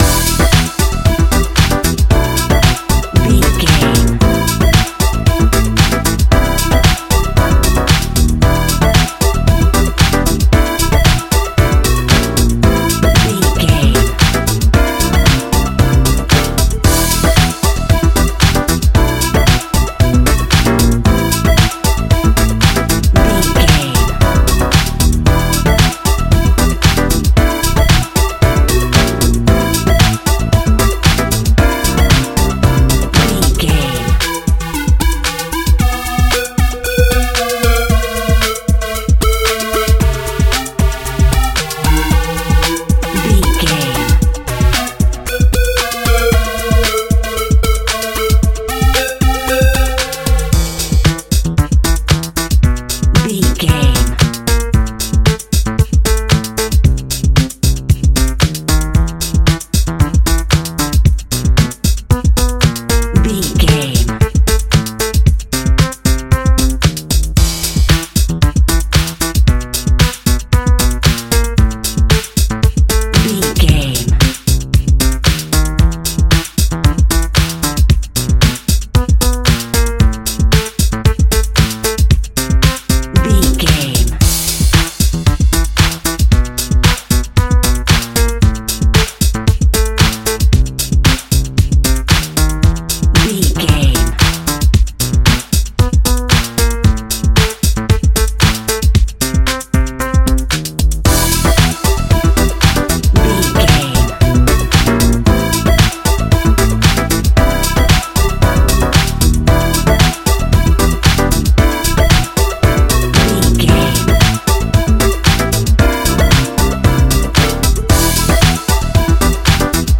Laid Back Funky Dance Music.
Aeolian/Minor
groovy
uplifting
driving
energetic
repetitive
bass guitar
electric piano
synthesiser
electric guitar
drums
electro house
drum machine